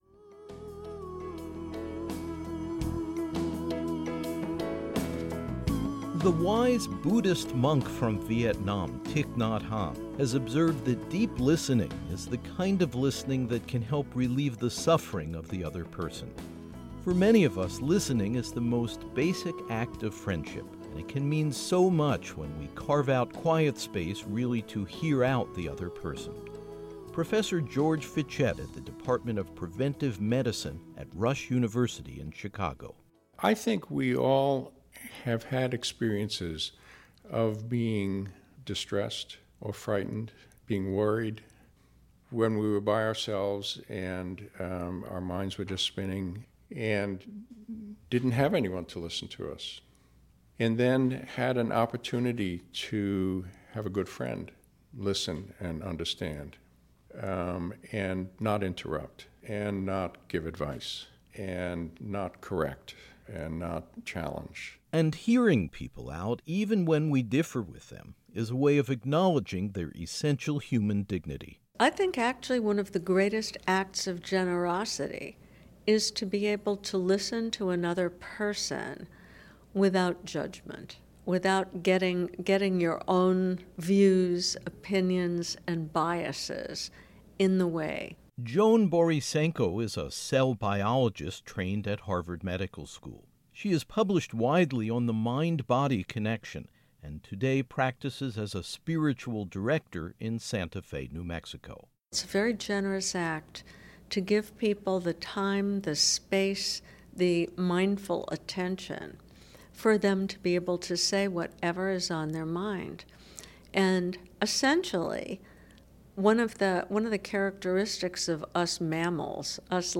There’s a kind of mystery in sitting calmly, patiently, attentively and tuning into someone else’s personal story and life journey. In this special program, you’ll hear the reflective insights of people who listen for a living: physicians, counselors and clergy.